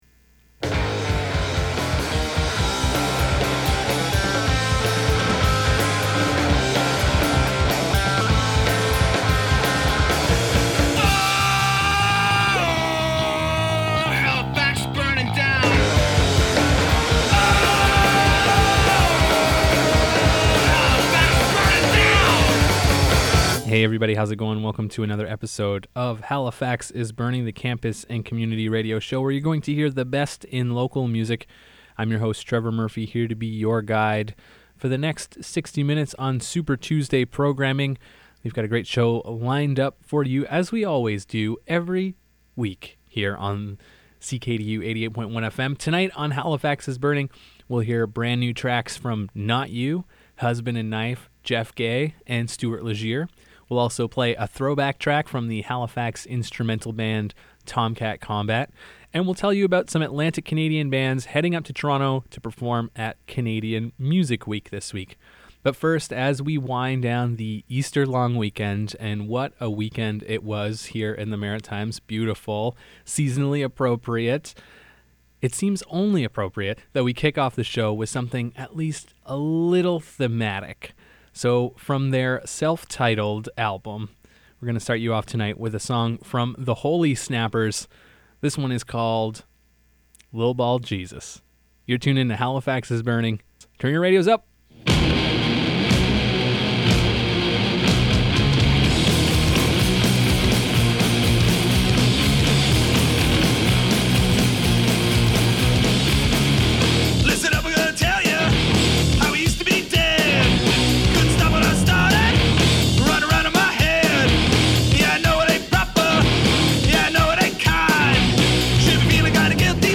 The best independent East Coast music